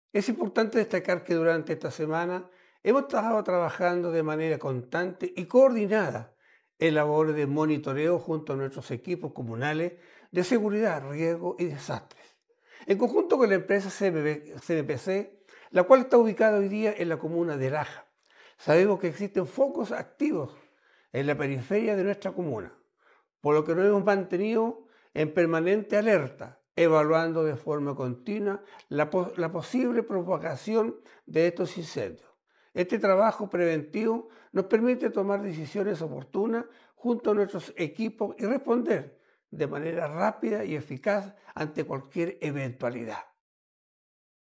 alcalde-yumbel.mp3